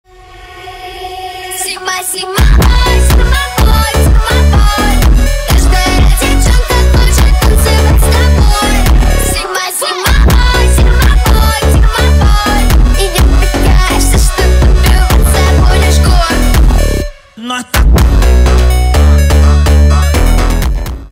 электроника
фонк
битовые , басы , качающие